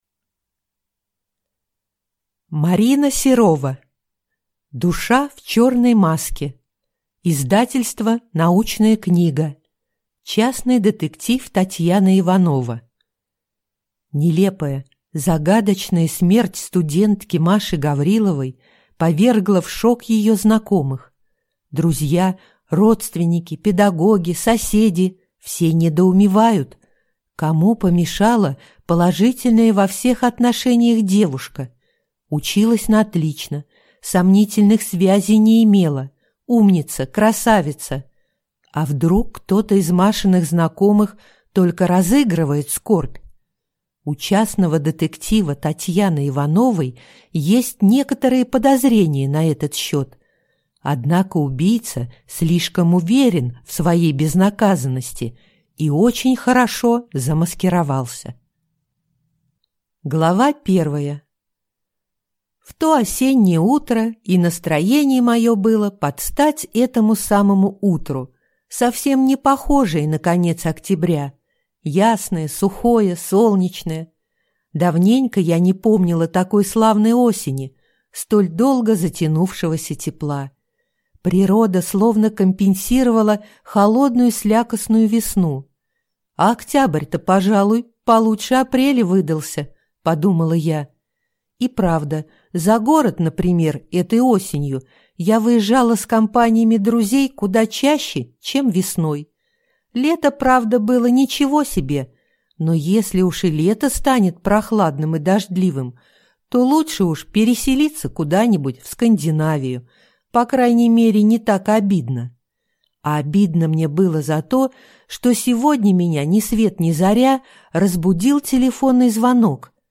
Аудиокнига Душа в черной маске | Библиотека аудиокниг